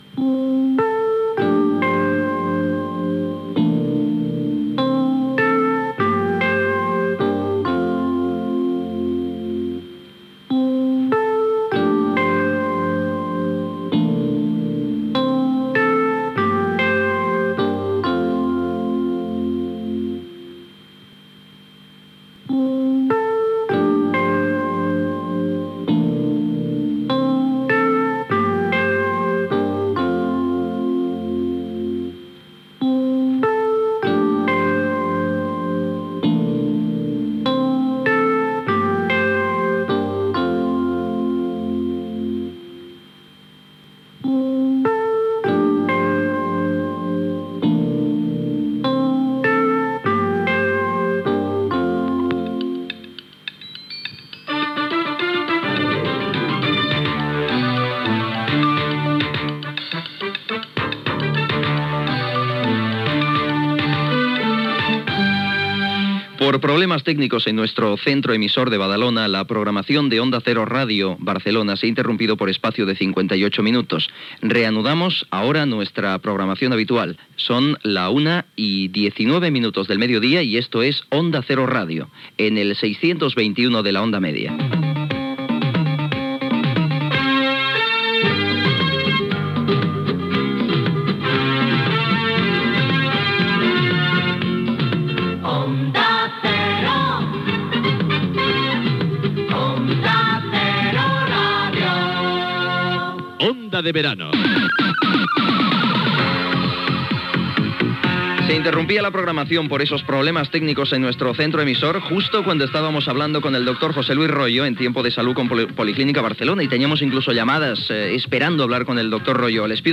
Sintonia de l 'emissora, represa de la programació després d'una interrupció. Hora, freqüència, indicatiu de l'emissora. Programa "Tiempo de verano", amb publicitat, hora i tema musical
Entreteniment